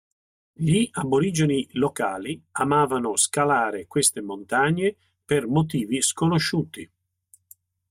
/monˈtaɲ.ɲe/